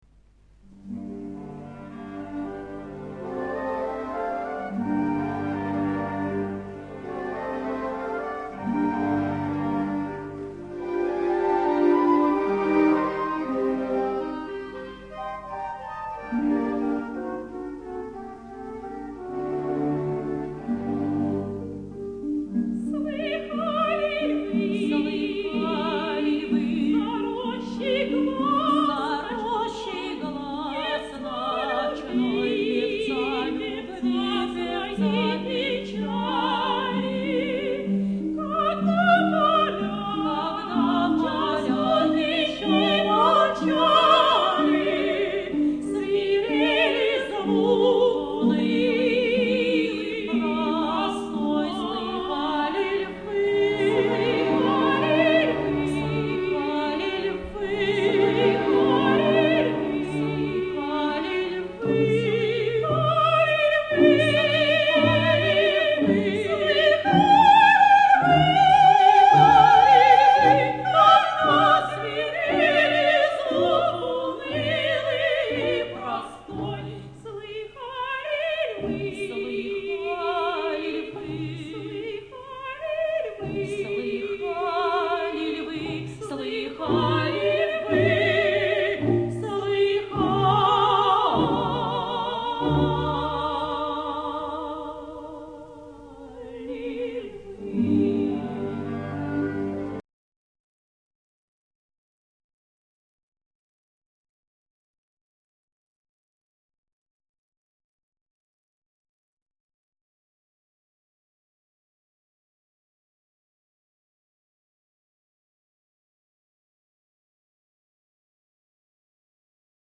анонимный дуэт
Оба дуэта поют всерьез (по нотам, в оригинальной тональности) первый куплет известного дуэта Татьяны и Ольги (второго куплета, как такового, в опере нет, там он превращается в квартет). Я, КАК СЕКУНДАНТ, представляю дуэт1 и, соответственно Татьяну1 (верхний голос, сопрано) и Ольгу1 (нижний голос, меццо-сопрано).